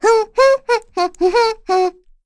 Pansirone-Vox_Hum.wav